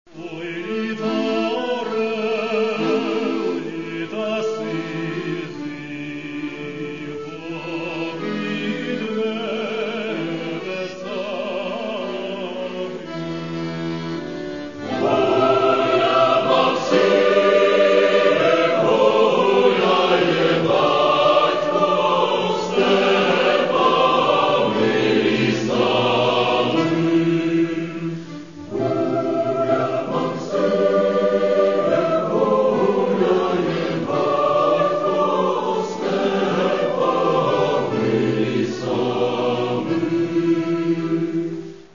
Українські народні пісні